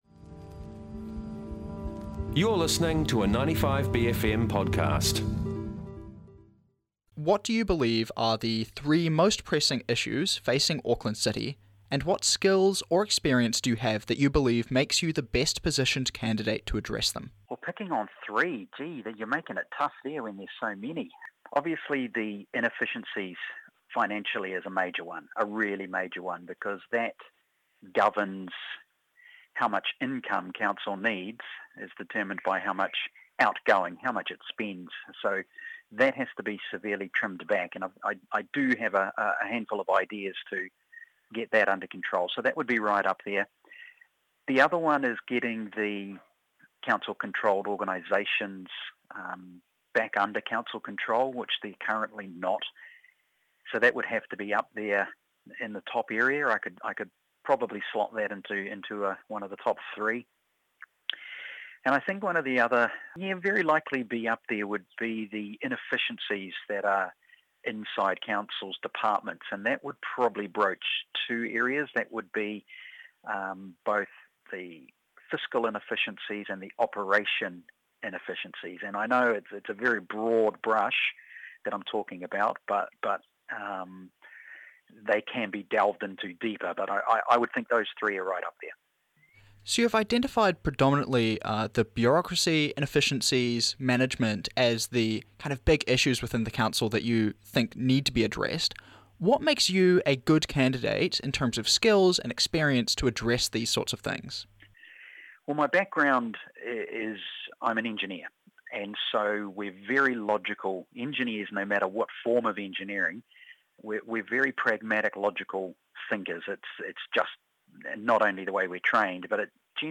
The Monday Wire includes our weekly catch-ups with the ACT Party's Simon Court. The Wire is 95bFM's long-running daily bastion of news, current affairs and views through the bFM lens.